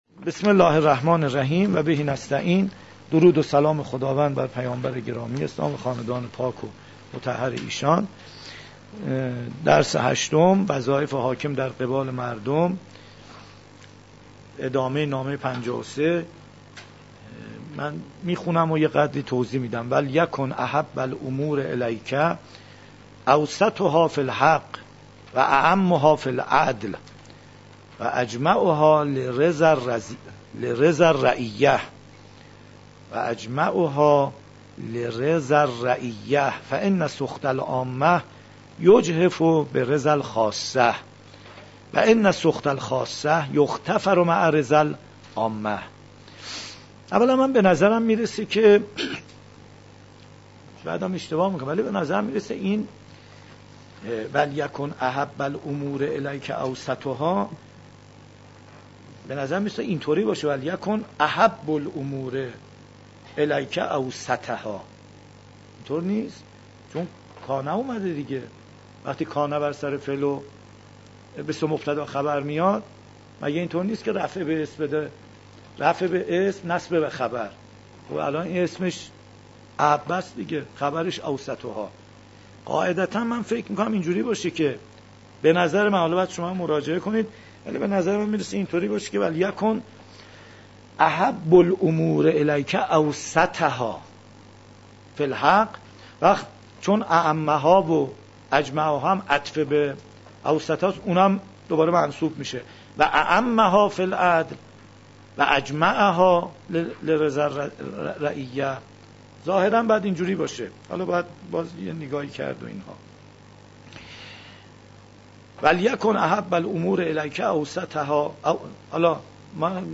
118 - تلاوت قرآن کریم